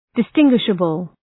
Shkrimi fonetik{dıs’tıŋgwıʃəbəl}
distinguishable.mp3